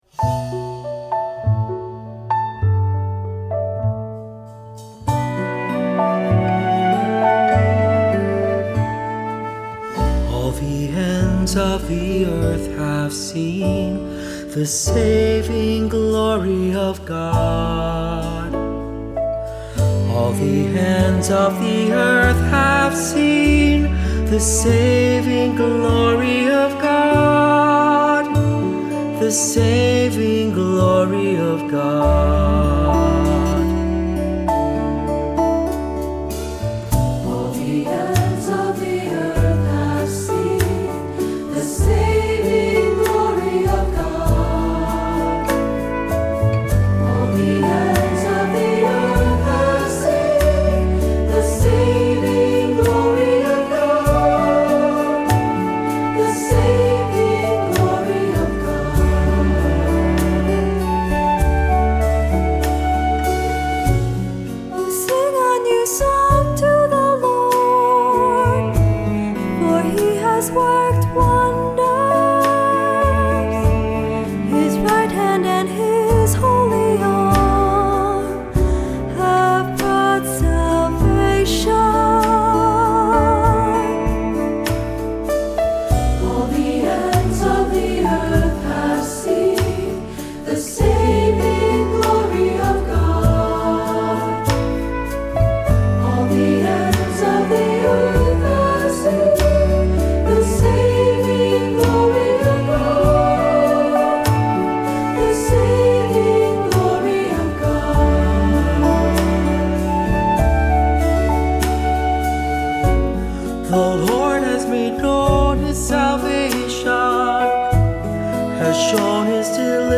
Accompaniment:      Keyboard
Music Category:      Christian
For one or two cantors or soloists.